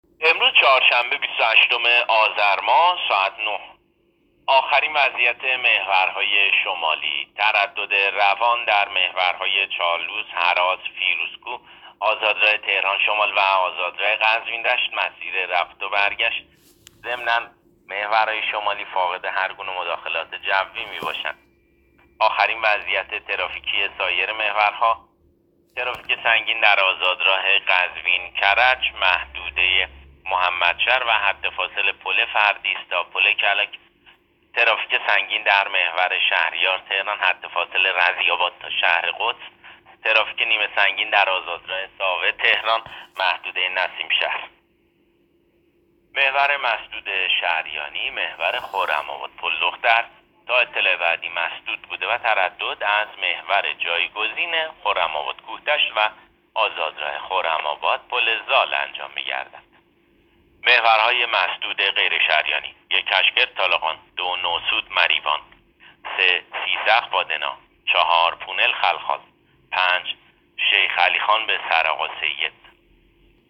گزارش رادیو اینترنتی از آخرین وضعیت ترافیکی جاده‌ها تا ساعت ۹ بیست و هشتم آذر؛